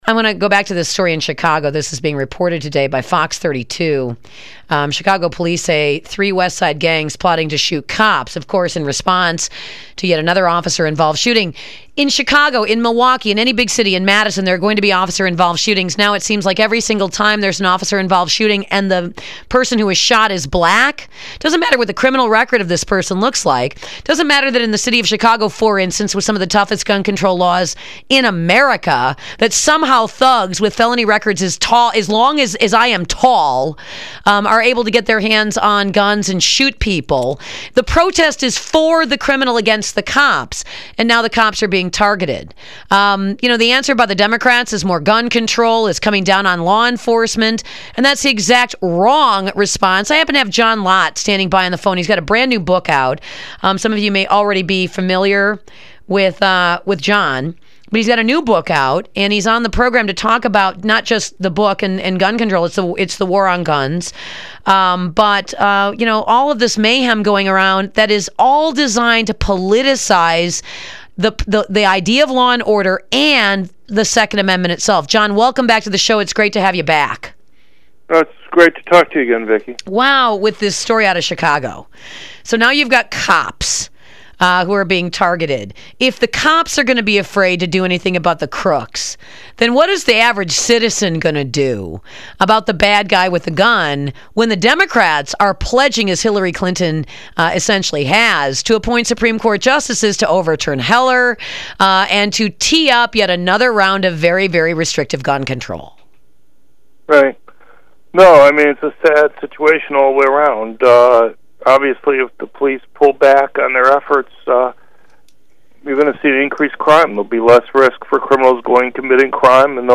radio show to discuss how Democrats make it difficult for poor blacks to buy guns